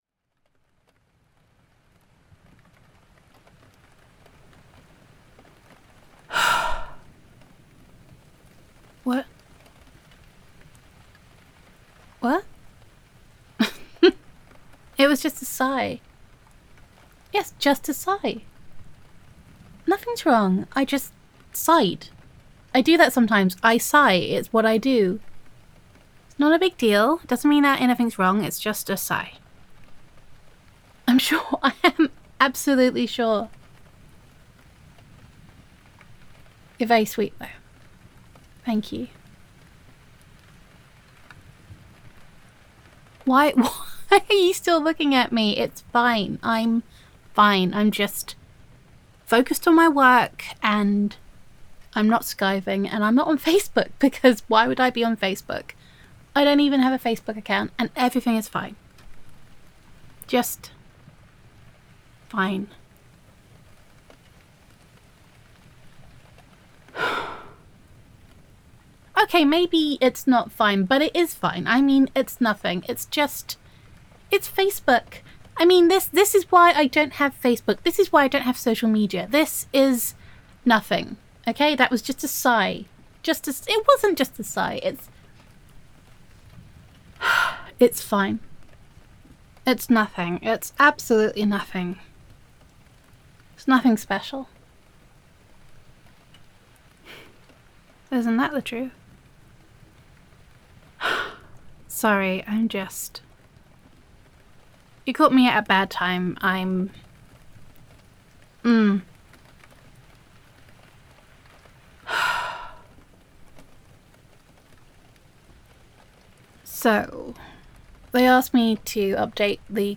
Downloads Download [F4A] Just a Sigh [Co-workers to Lovers][Office Crush].mp3 Content So, you’ve caught me spiralling over my ex—want to stick around for the meltdown?
Audio Roleplay